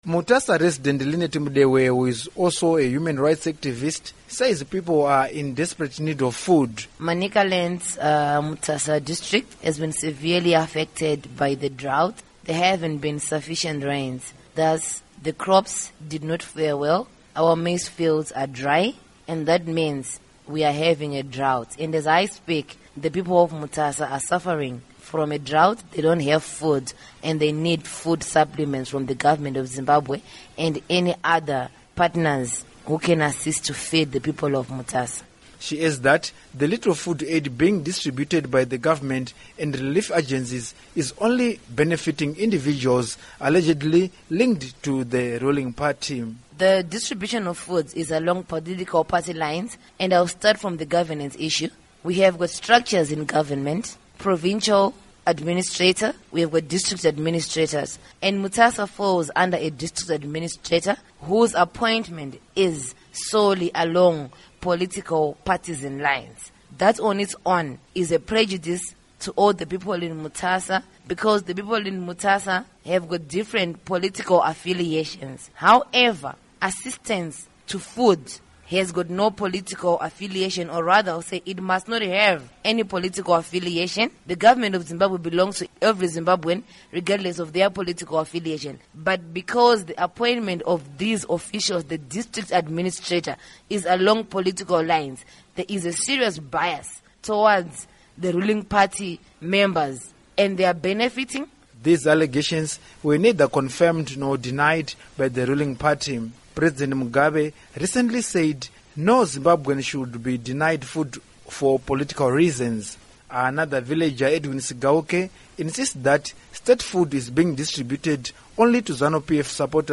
Report on Manicaland Hunger